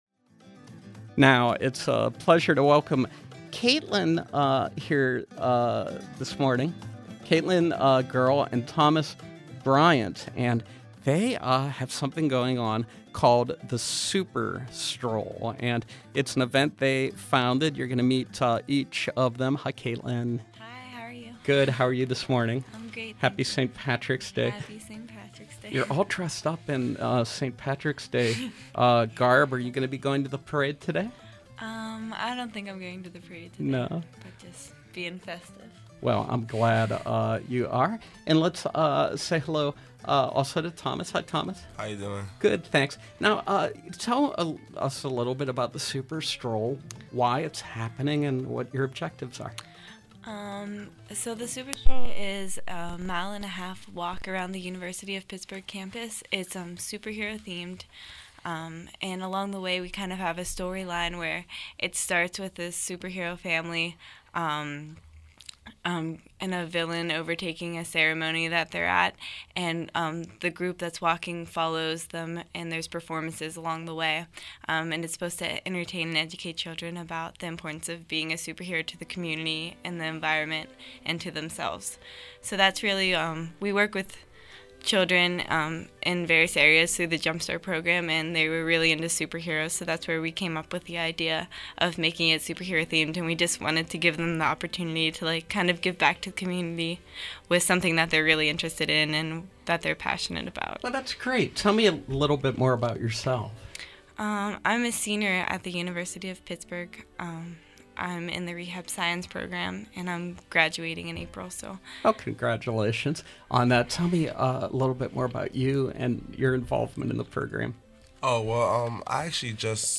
Home » Events, Featured, Interviews